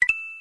smw_coin.wav